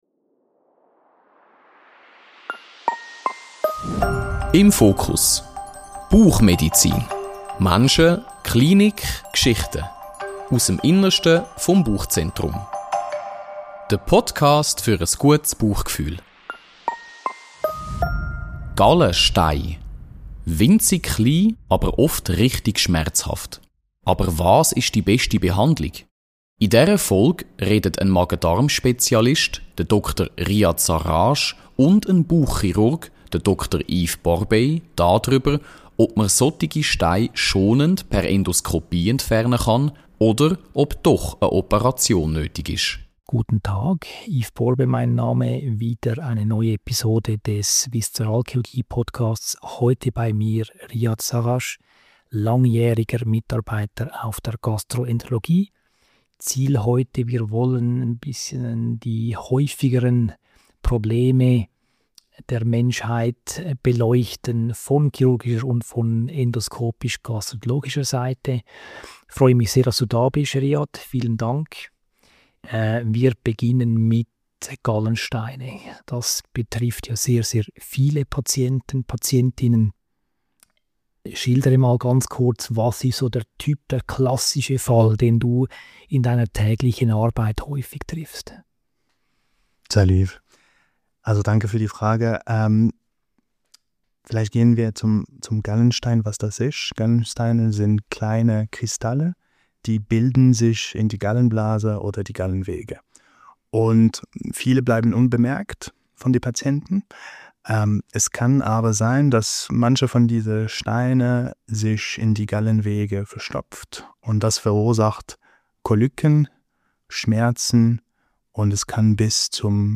Ein sachliches und zugleich gut verständliches Gespräch über Diagnostik, Risiken und moderne Behandlungsstrategien bei Gallensteinen.